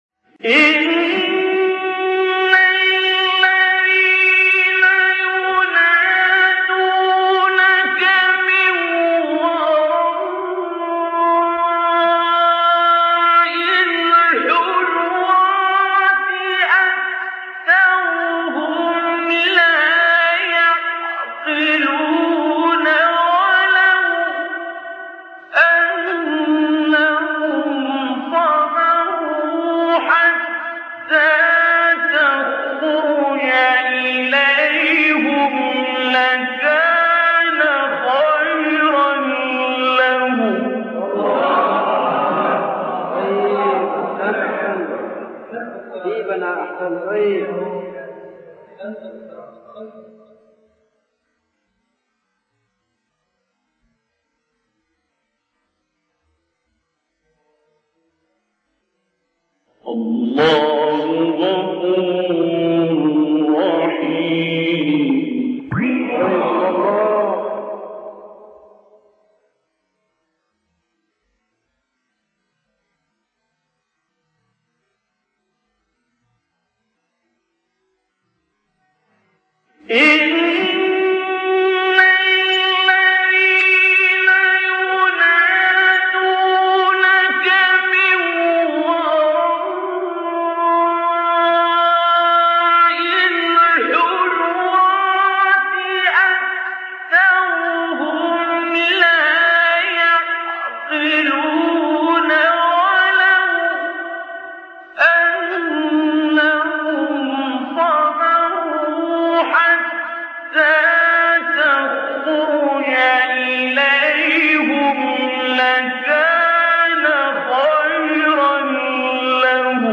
آیه 4-6 سوره حجرات استاد عبدالباسط محمد عبدالصمد | نغمات قرآن | دانلود تلاوت قرآن